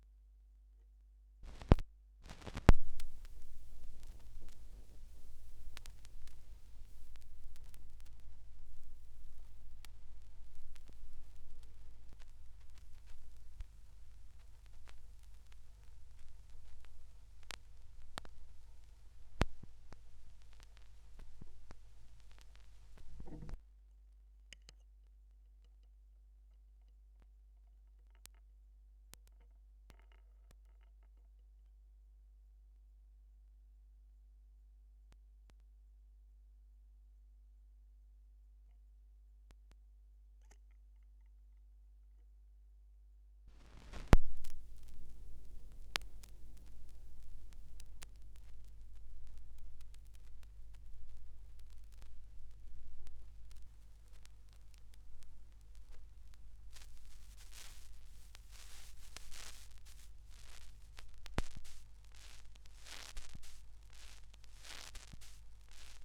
2017 Schallplattengeräusche (3).m3u